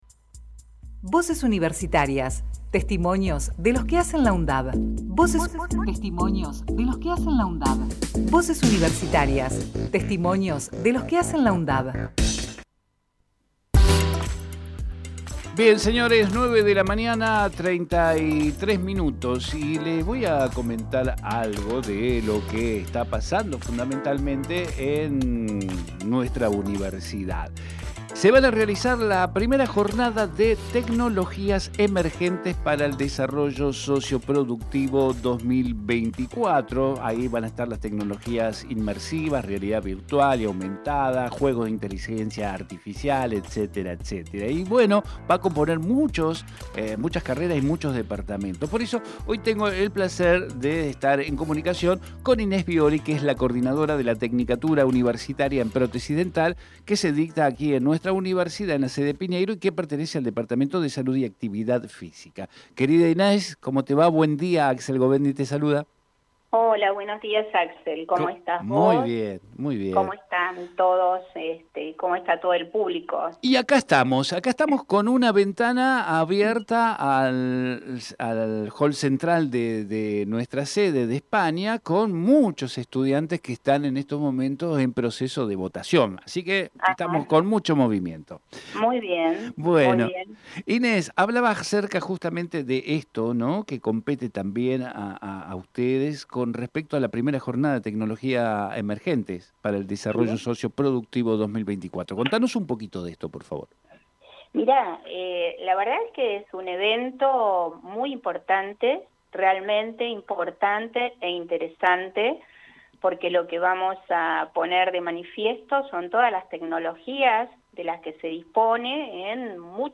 Compartimos entrevista realizada en "Territorio Sur"